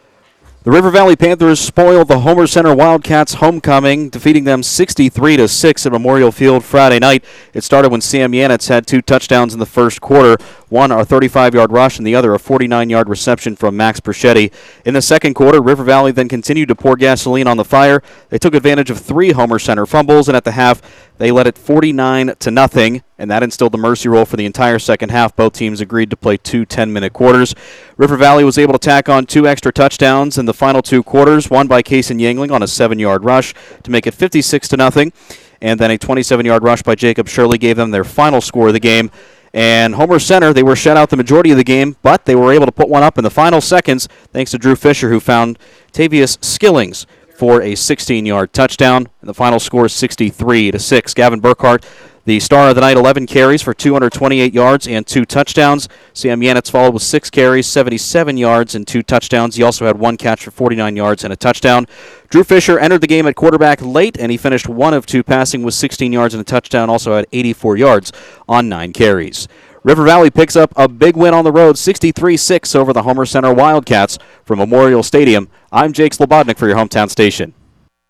recaps the game, which was heard on WCCS and Cat Country and watched in Renda Digital TV.